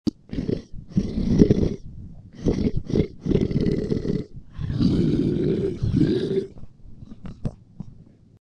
Nu metal ������� �� ����� - guitar.demo - ����� ����������